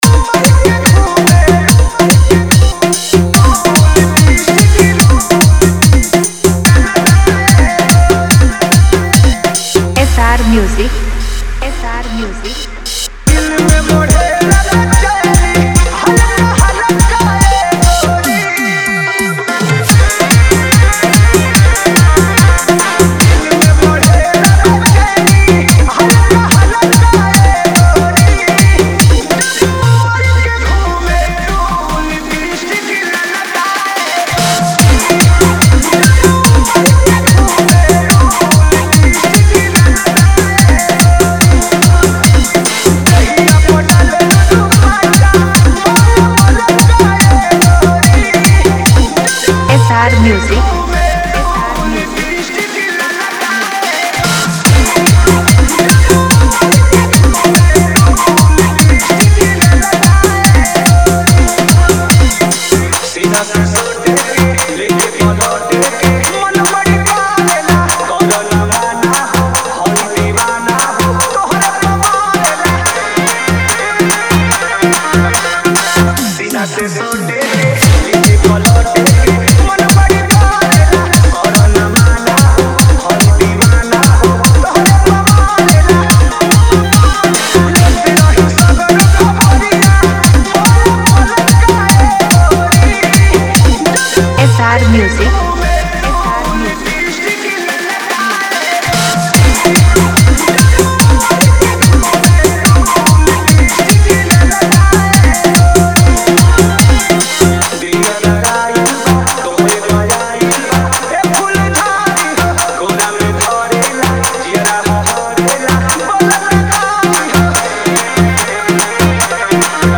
Bhojpuri Dj Songs